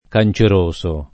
canceroso [ kan © er 1S o ]